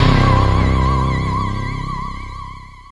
Index of /90_sSampleCDs/Zero-G - Total Drum Bass/Instruments - 1/track10 (Pads)